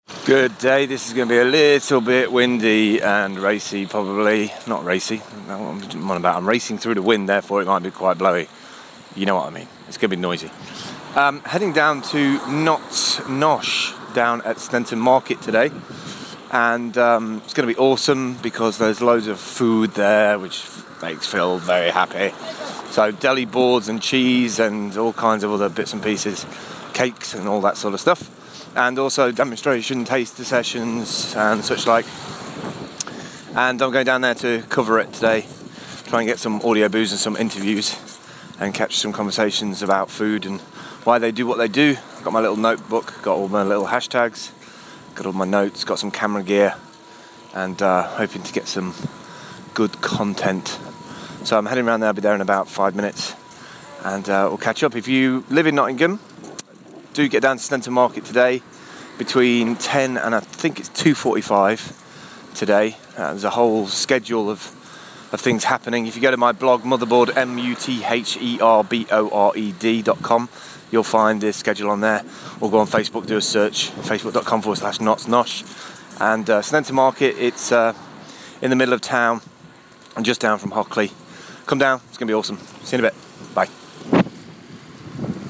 Notts Nosh @ Sneinton Market Today